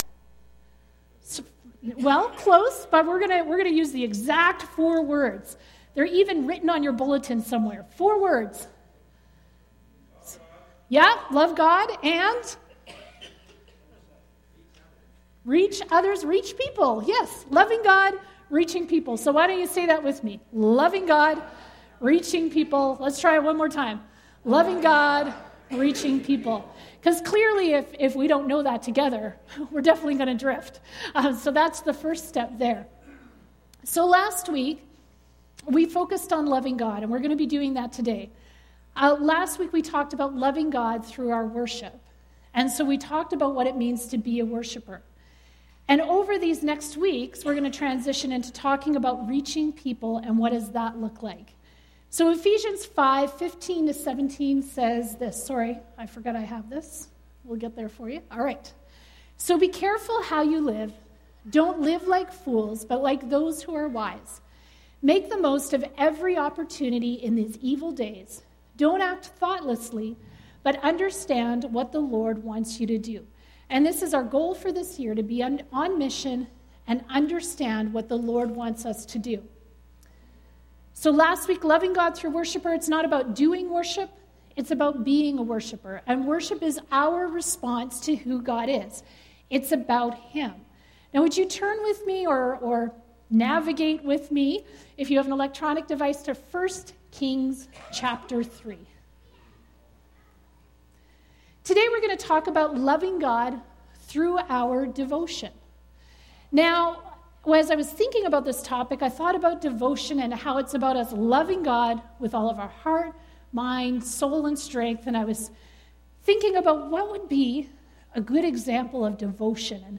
Passage: 1 Kings 3:3, 5; 1 Kings 11:1-13 Service Type: Sunday Service